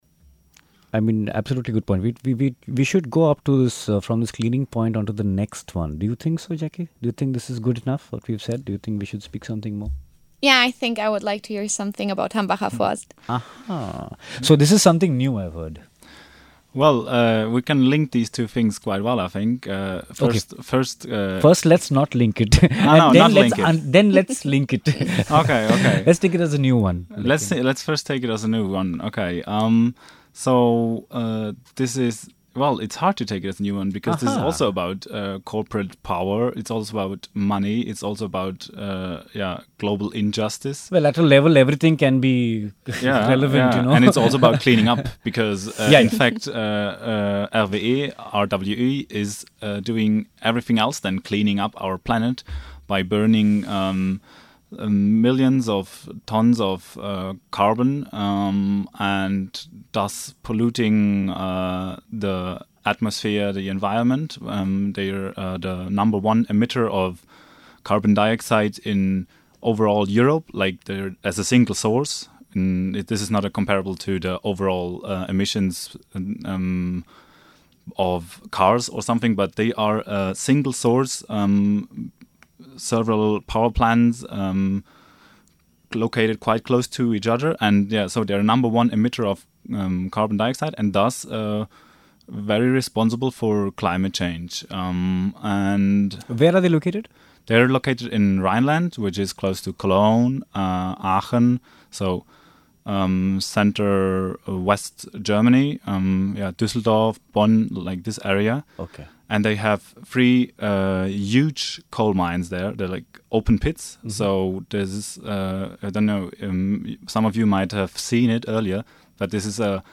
Here is a chat with some from the journalistic and research fields simply appearing on the Newcomer News show to make us aware of two upcoming demonstrations taking place in our democratic surrounding currently.